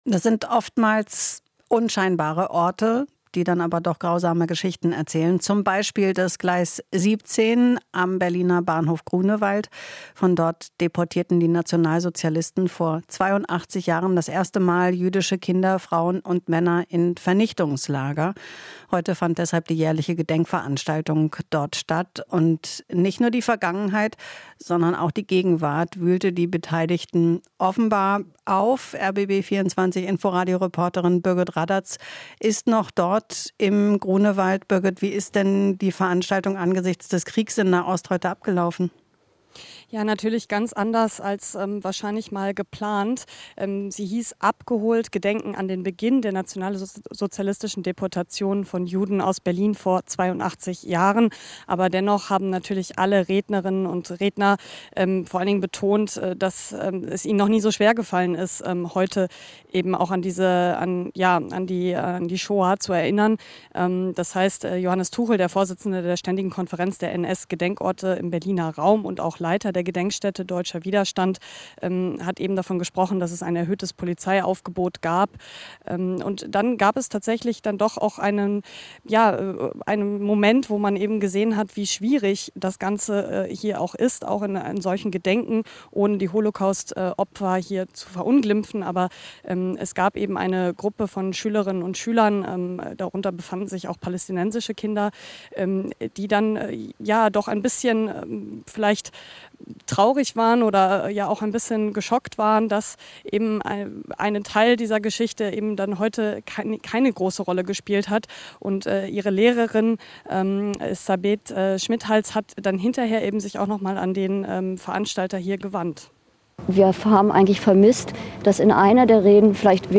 Interview - Holocaustgedenken am Gleis 17 in Zeiten der Nahost-Krise